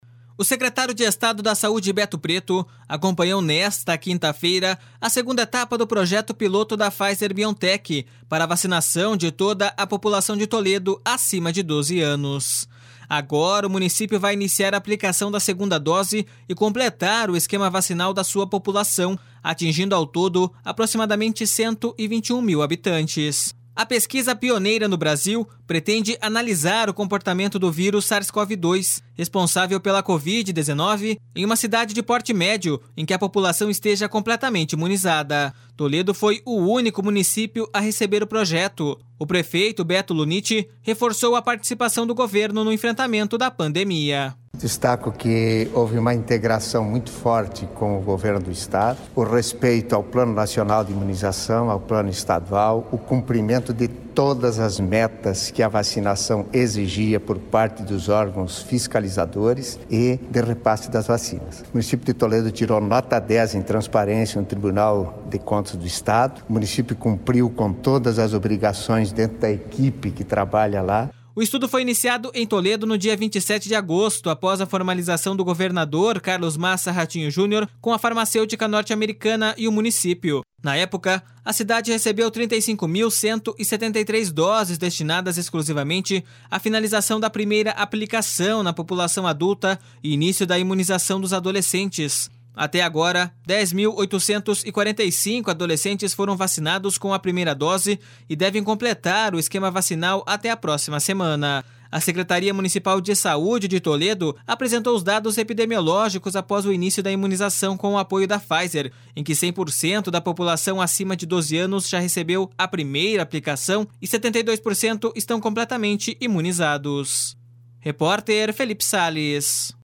O prefeito, Beto Lunitti, reforçou a participação do Governo no enfrentamento da pandemia.// SONORA BETO LUNITTI.// O estudo foi iniciado em Toledo no dia 27 de agosto após a formalização do governador Carlos Massa Ratinho Junior com a farmacêutica norte-americana e o município.